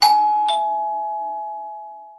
Sound Effects
Doorbell Wall